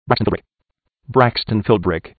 A spearcon is a brief non-speech sound that is created by speeding up the TTS phase in particular ways, even to the point where the spearcon is no longer recognizable as a particular word. Often a spearcon is prepended to each TTS menu item, and leads to faster, more accurate, and more enjoyable navigation through a menu.
Spearcon samples: [elephant.wav] [elevator.wav] [